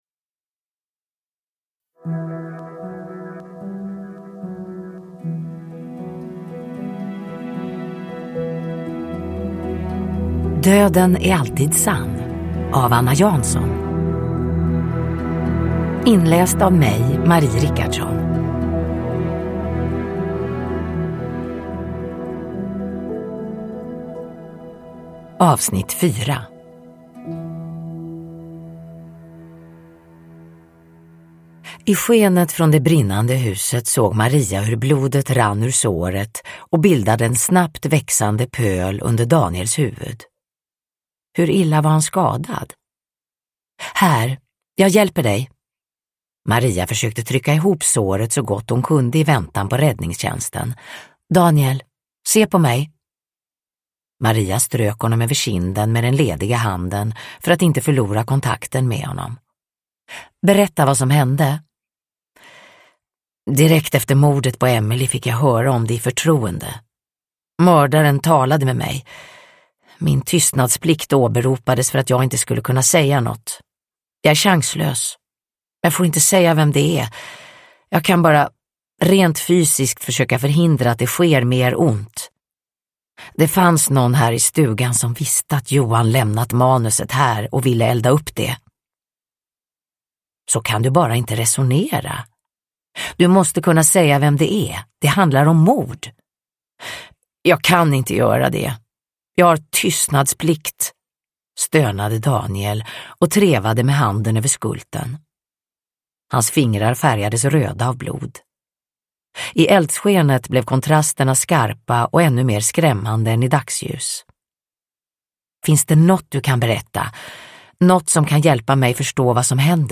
Uppläsare: Marie Richardson